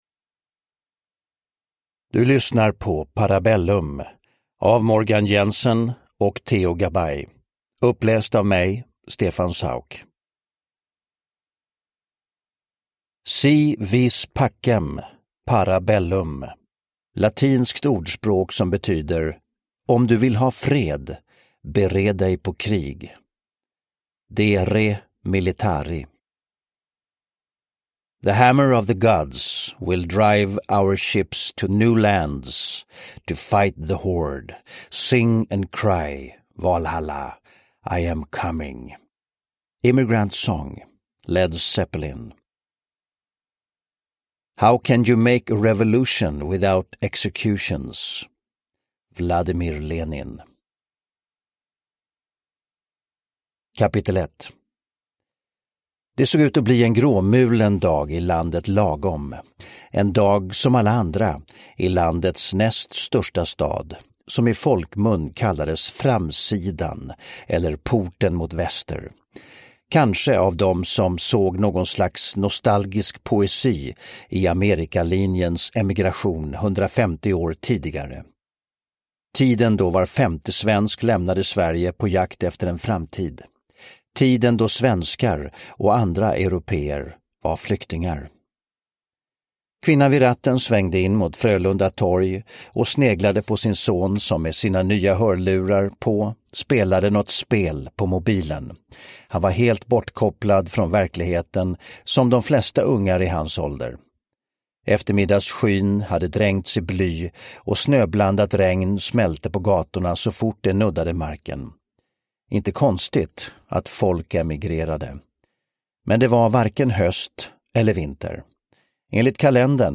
Parabellum (ljudbok) av Theo Gabay